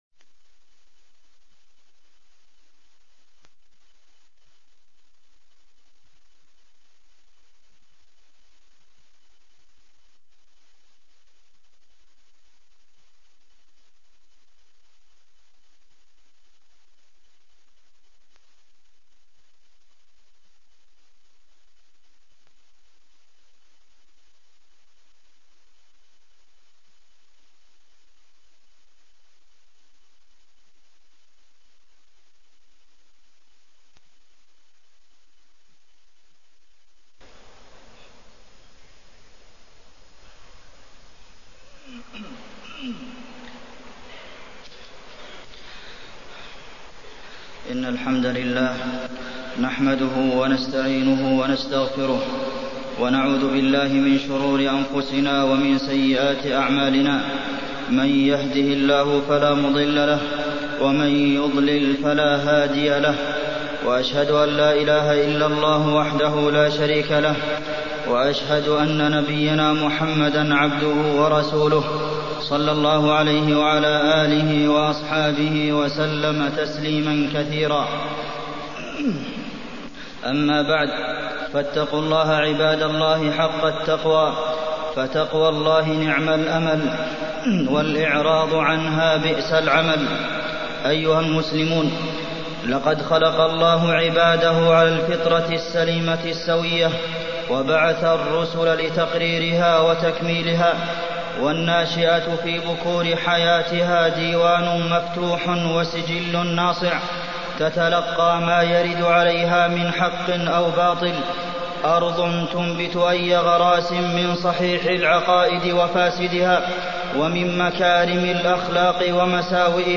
تاريخ النشر ٢٩ ربيع الأول ١٤٢٤ هـ المكان: المسجد النبوي الشيخ: فضيلة الشيخ د. عبدالمحسن بن محمد القاسم فضيلة الشيخ د. عبدالمحسن بن محمد القاسم إحترام العلماء